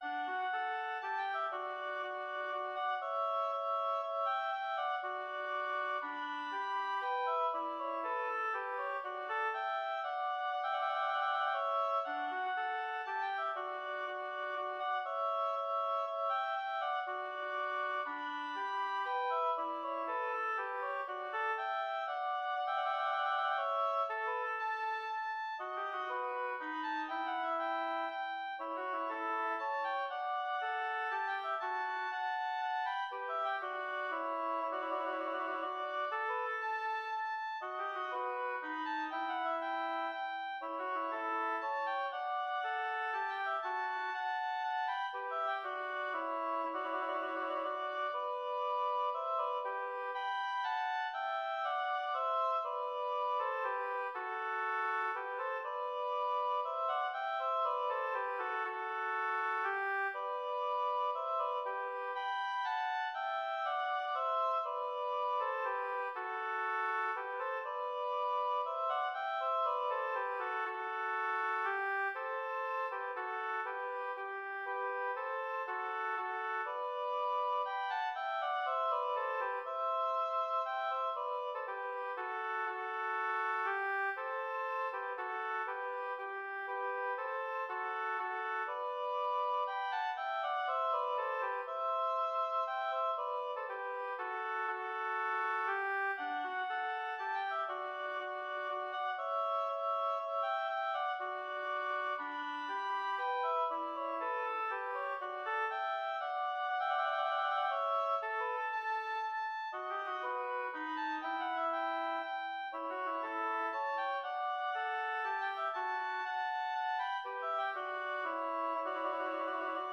Intermediate oboe duet